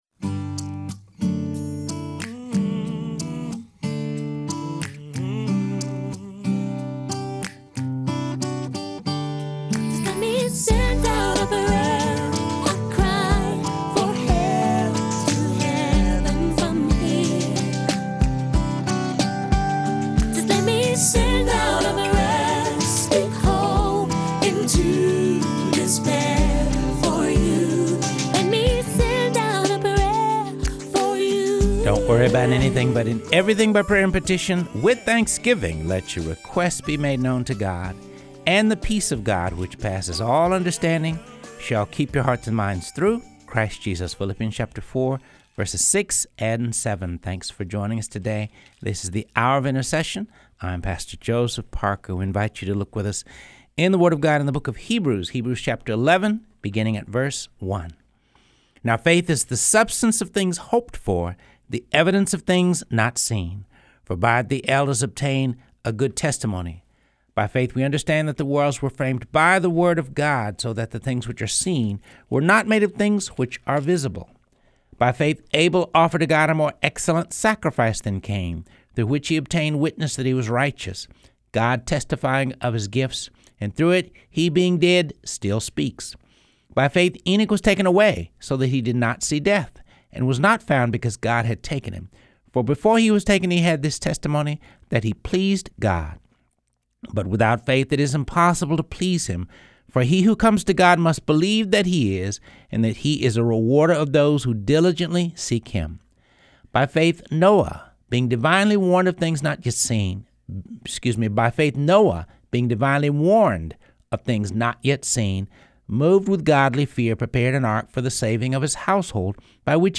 Reading through the Word of God | Episode 59